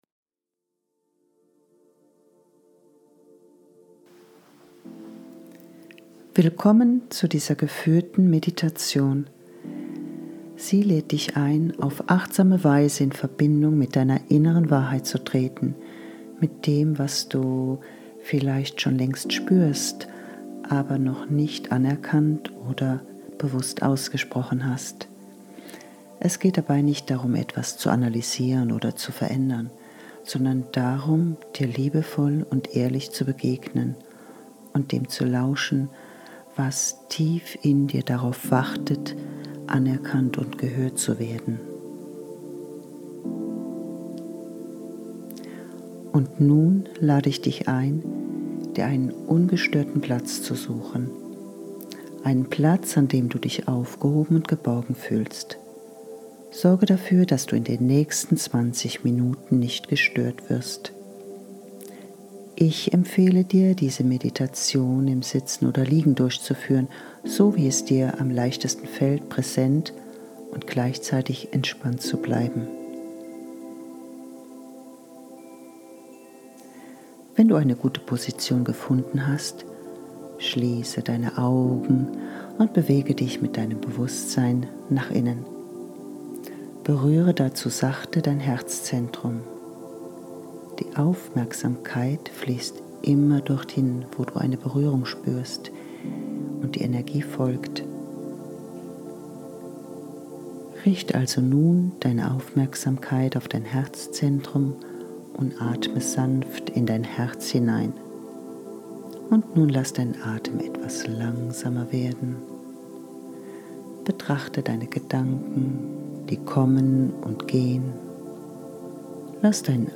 Innere Übung – Geführte Meditation: Was darf jetzt gehen?